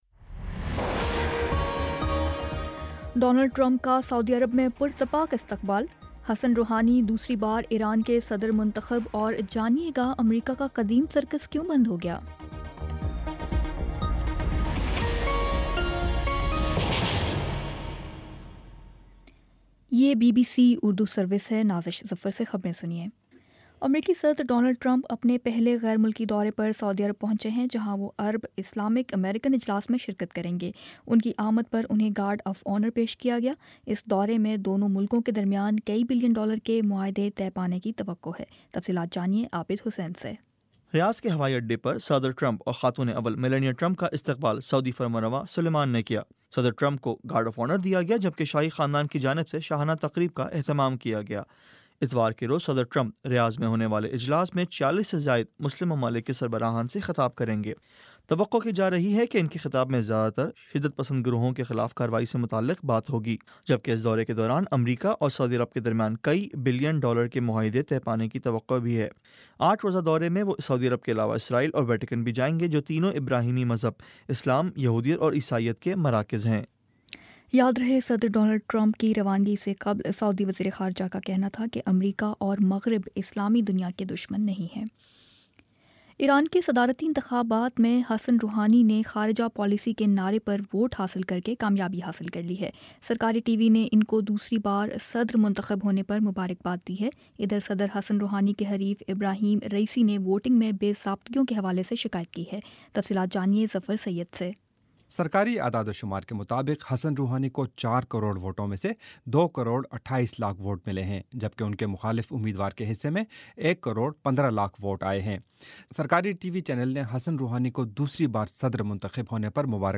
مئی 20 : شام سات بجے کا نیوز بُلیٹن